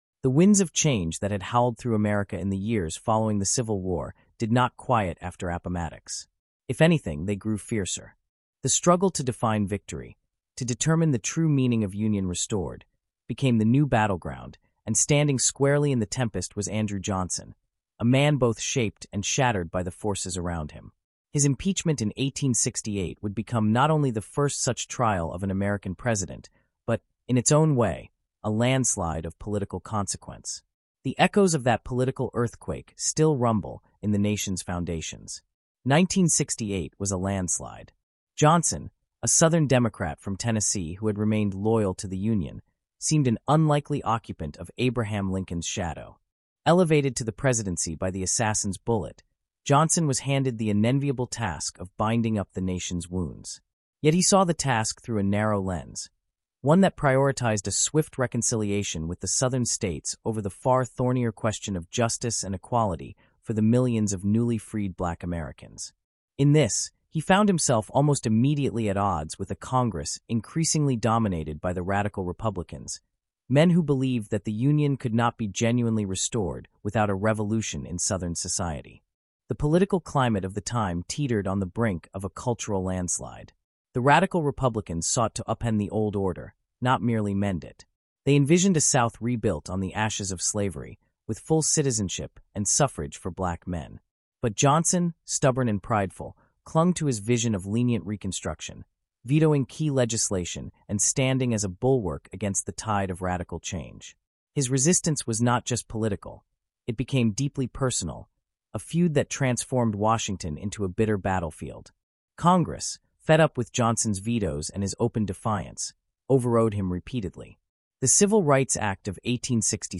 Disclosure: This podcast includes content generated using an AI voice model.